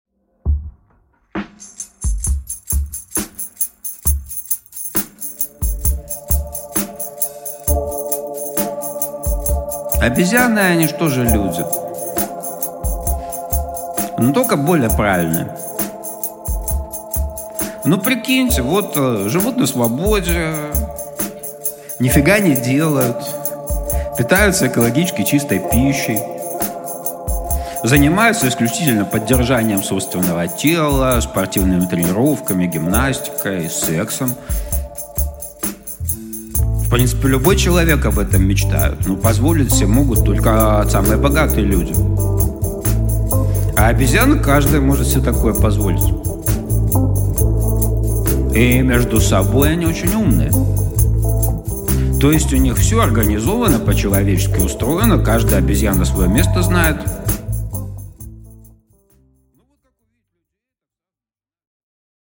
Аудиокнига Про обезьян | Библиотека аудиокниг